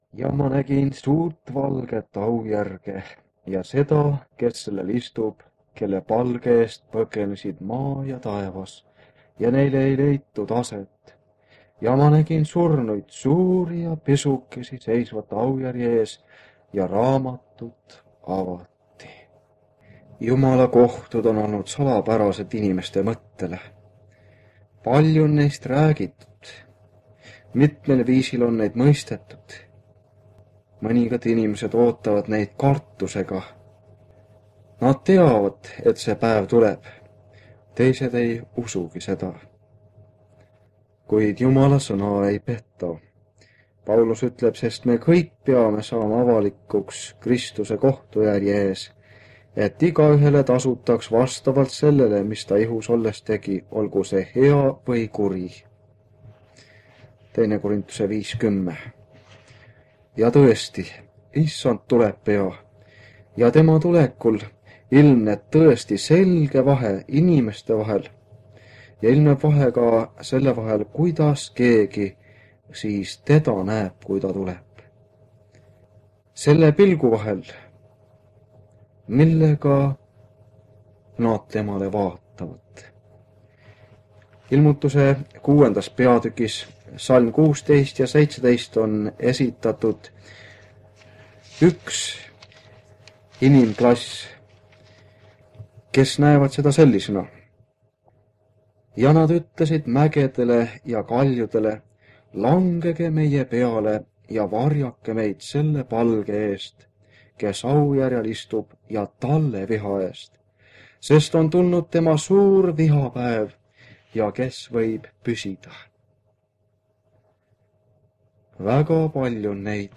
Ilmutuse raamatu seeriakoosolekud Kingissepa linna adventkoguduses